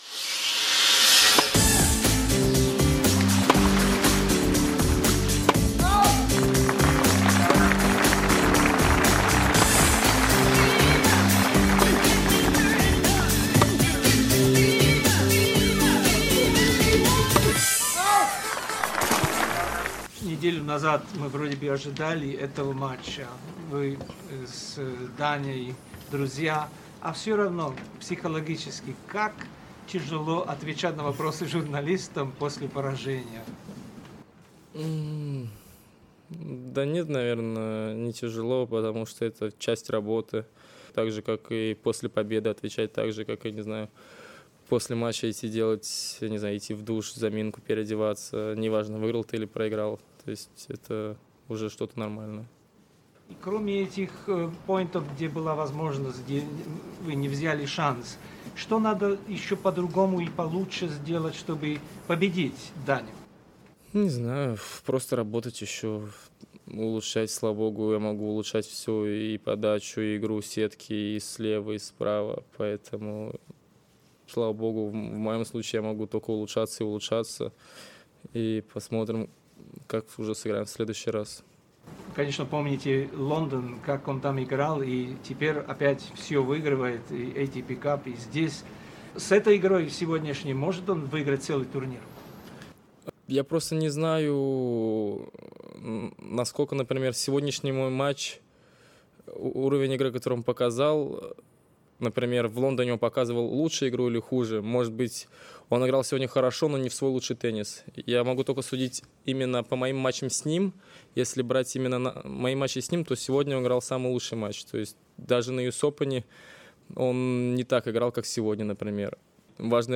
Interview with a Russian tennis player Andrey Rublev at the Australian Open 2021.